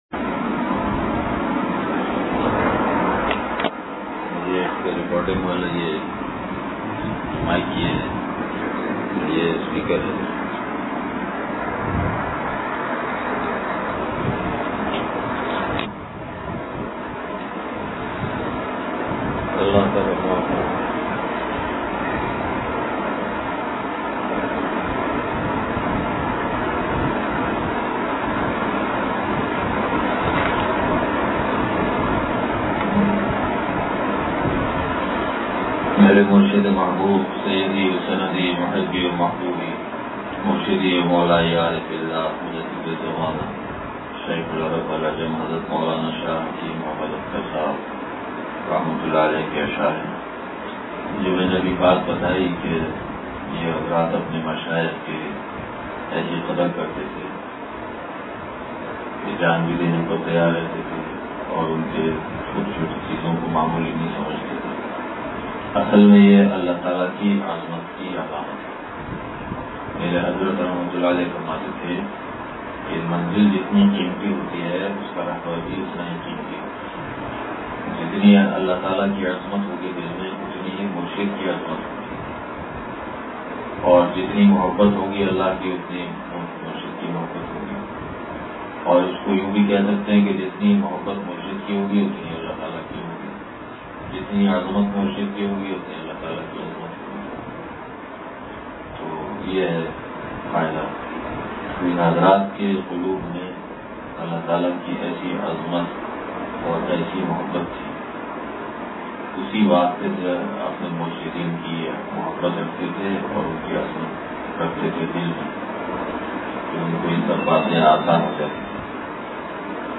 بیان – کوکن